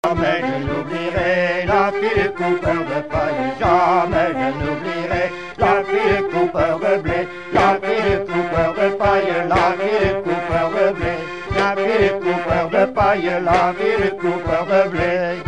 danse du baton
Couplets à danser
Pièce musicale inédite